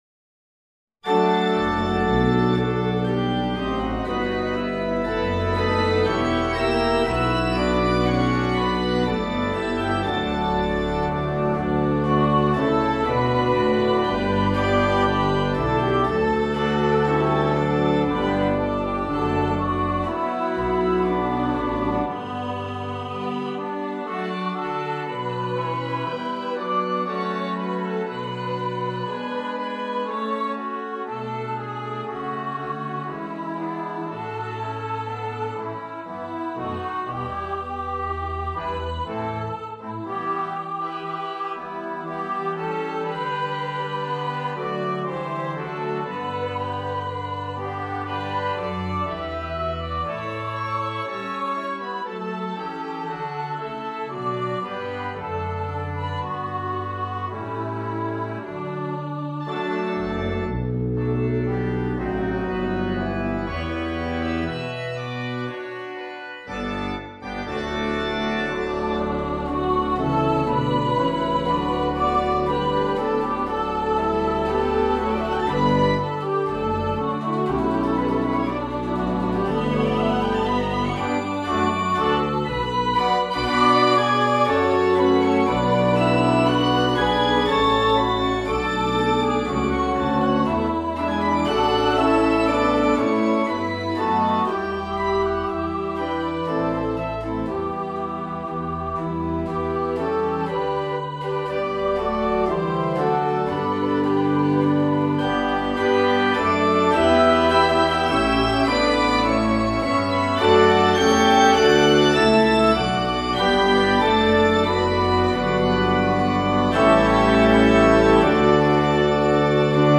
for voices & organ